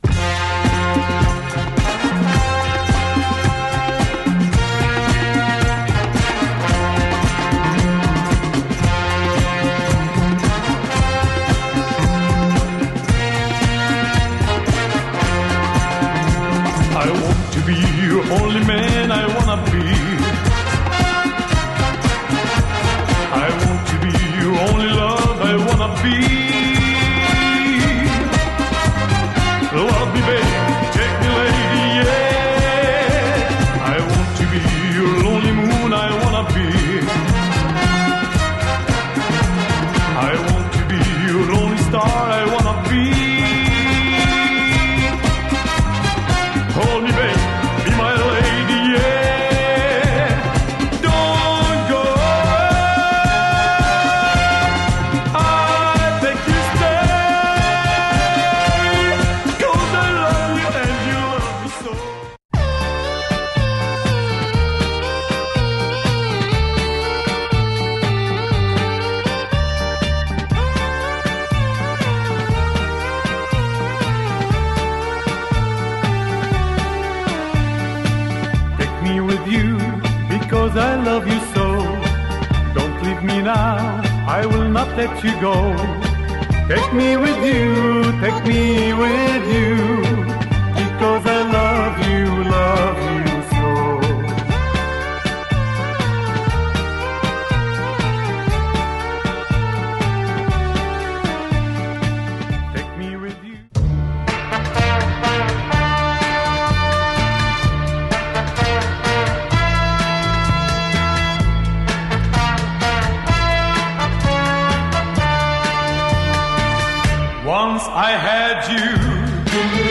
Lebanese disco pop !